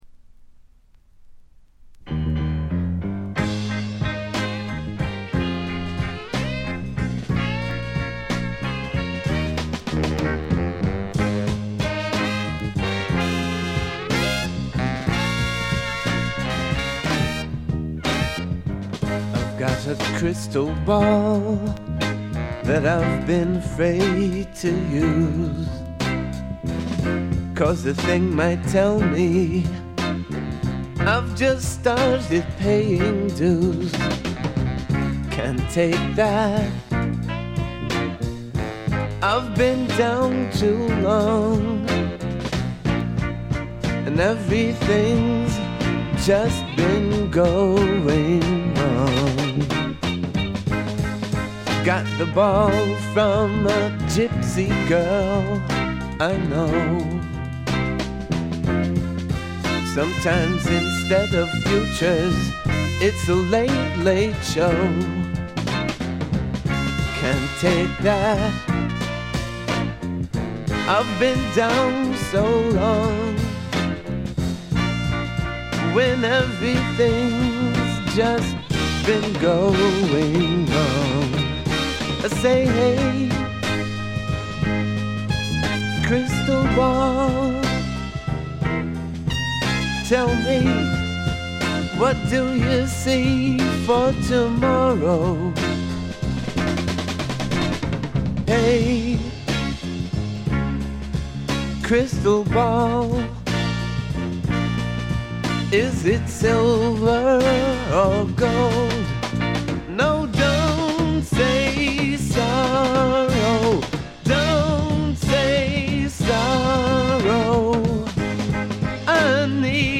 ほとんどノイズ感無し。
もともとは楽曲ライター志望だったようで曲の良さはもちろんのこと、ちょっとアシッドなヴォーカルが素晴らしいです。
メランコリックでビター＆スウィートな哀愁の名作。
試聴曲は現品からの取り込み音源です。